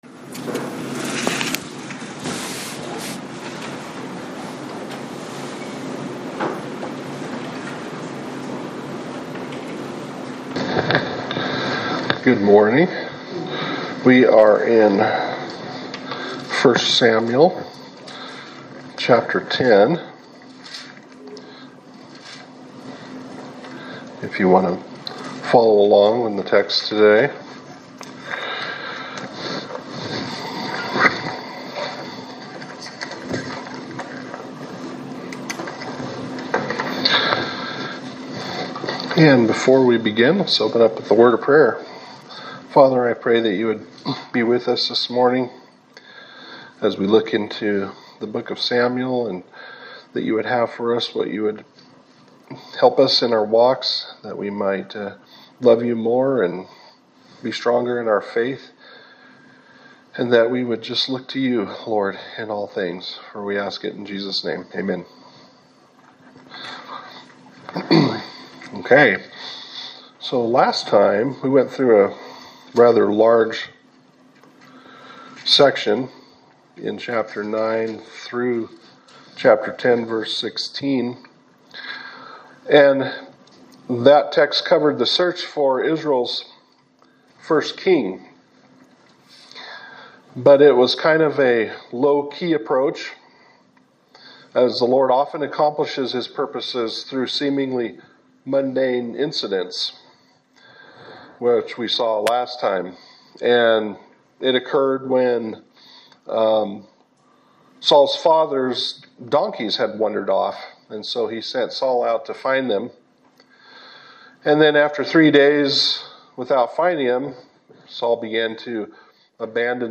Sermon for August 17, 2025
Service Type: Sunday Service